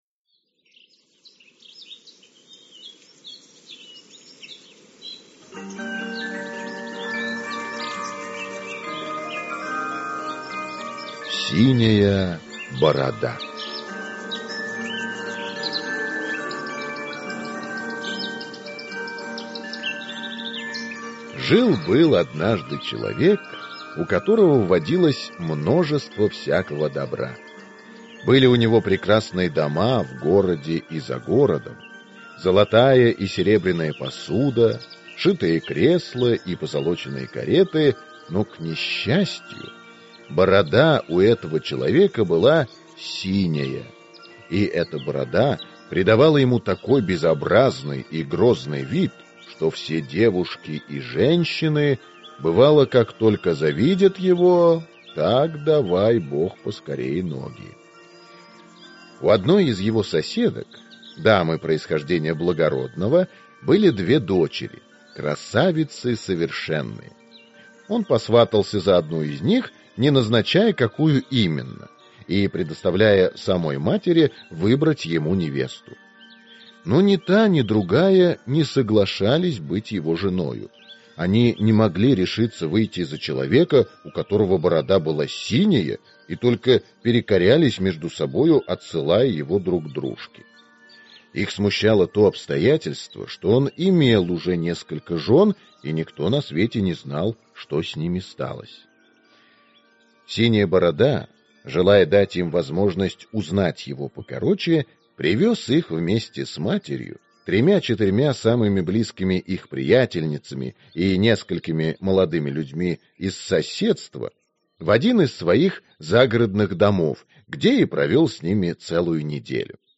Аудиокнига Синяя Борода | Библиотека аудиокниг